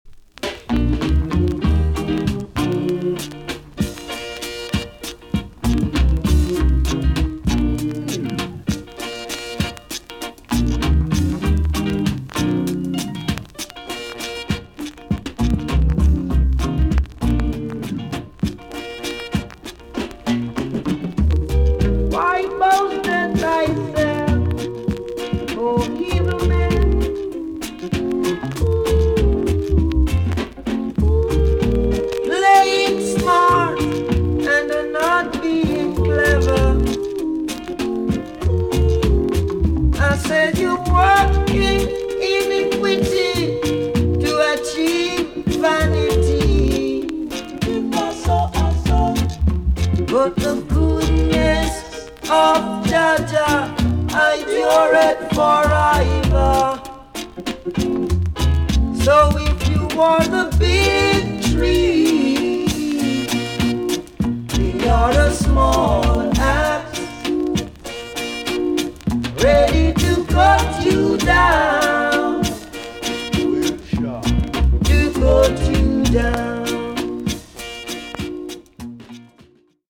TOP >LP >VINTAGE , OLDIES , REGGAE
A.SIDE VG+ 少し軽いチリノイズが入ります。